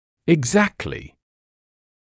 [ɪg’zæktlɪ][иг’зэктли]точно, в точности; совсем